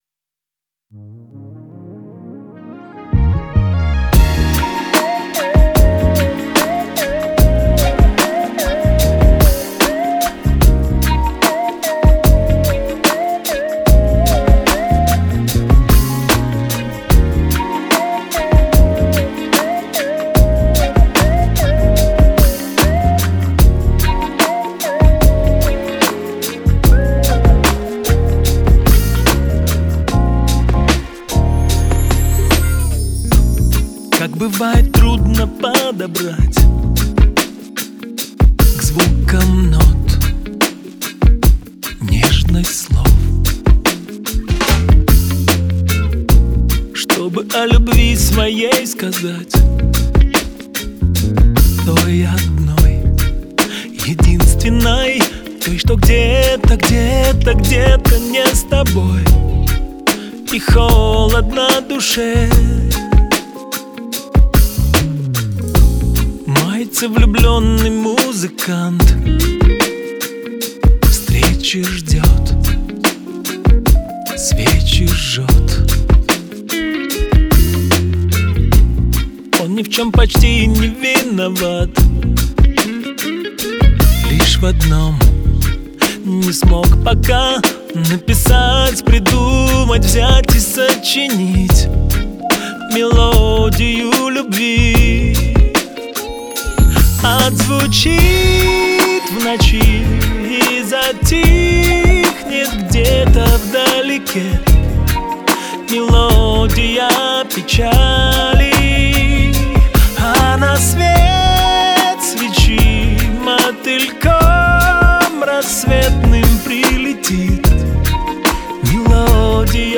это трогательная композиция в жанре народной музыки